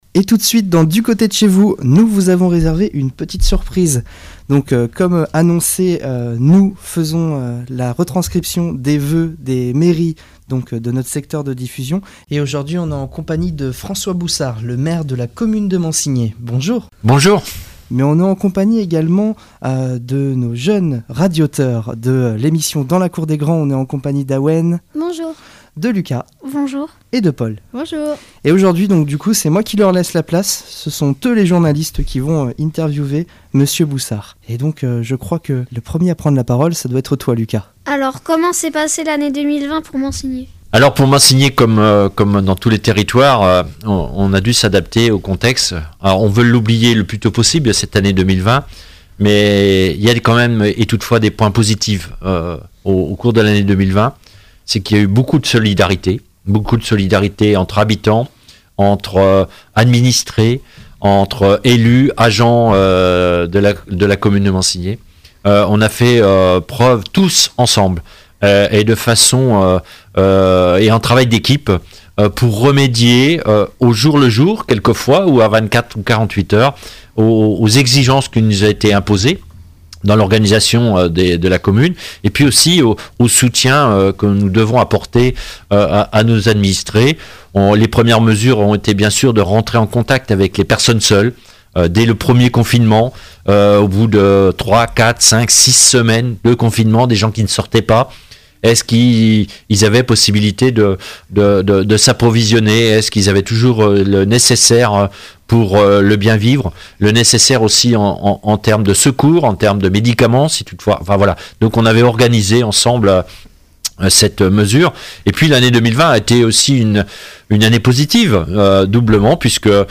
François Boussard est l'invité de la rédaction. Interrogé par des membres du Club radio, le maire de Mansigné revient sur le bilan de 2020 et fait part de ses voeux pour cette année 2021.